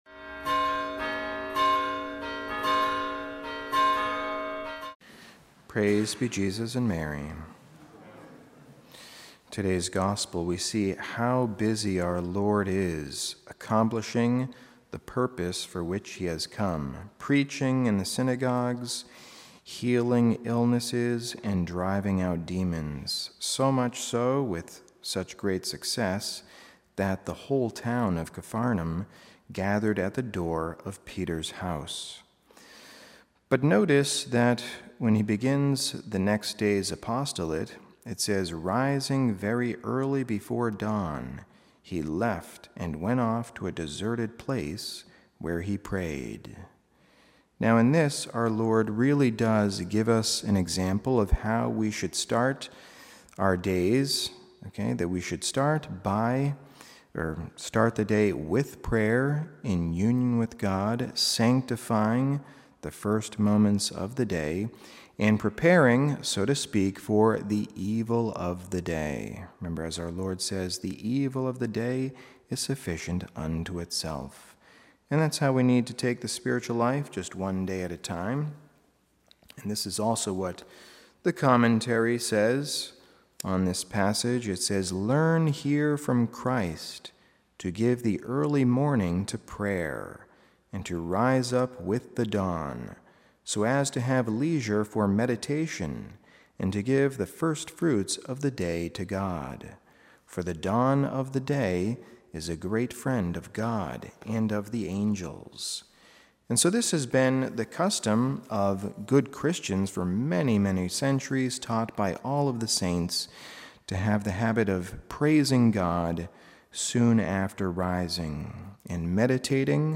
Homily
Mass: Wednesday 1st Week of Ordinary Time - Wkdy Readings: 1st: heb 2:14-18 Resp: psa 105:1-2, 3-4, 6-7, 8-9 0 Gsp: mar 1:29-39 Audio (MP3) +++